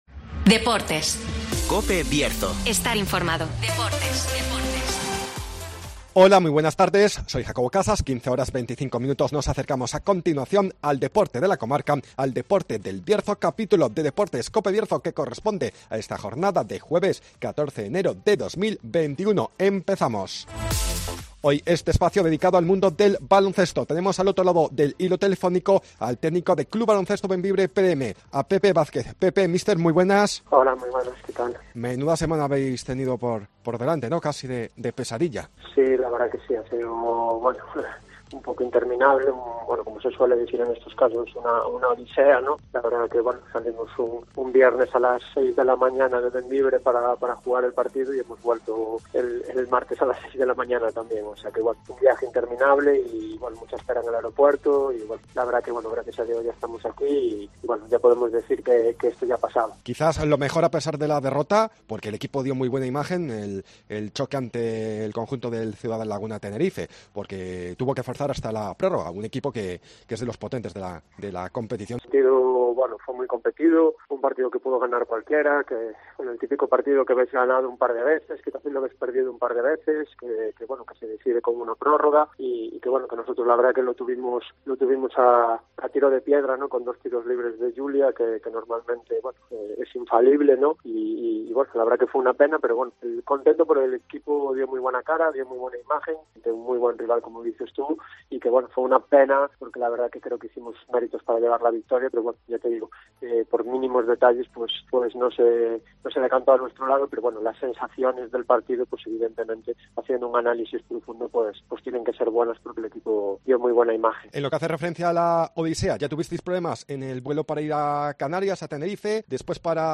Actualidad del basket con entrevista